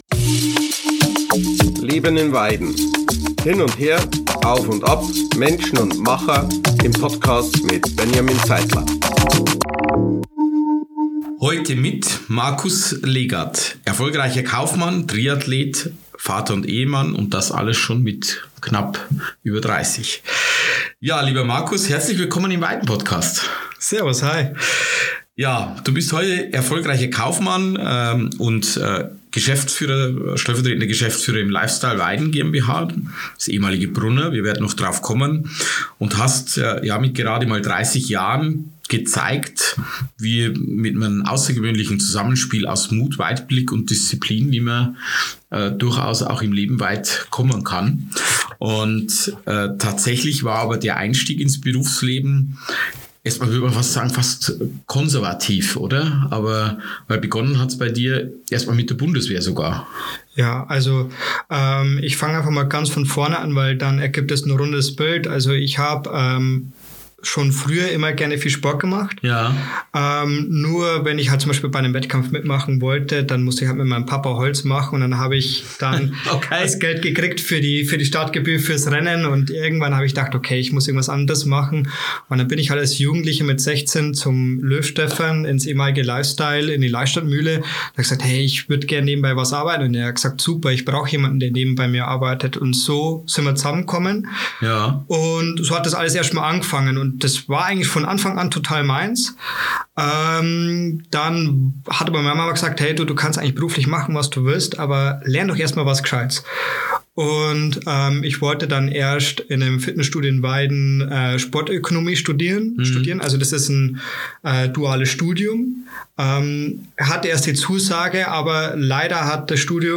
Ein Gespräch über berufliche Entscheidungen, sportliche Leidenschaft und die Frage, wie man unterschiedliche Rollen im Leben miteinander vereint.